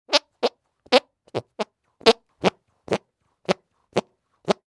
Звуки подмышки
Звук пука ладонями